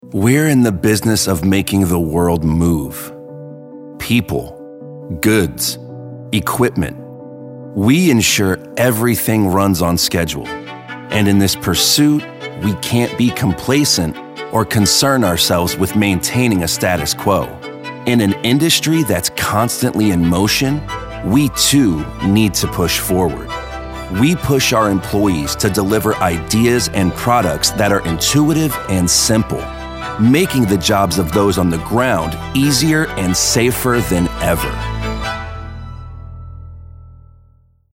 anti-announcer, compelling, concerned, confessional, conversational, genuine, inspirational, mellow, motivational, real, smooth, soft-spoken, thoughtful, warm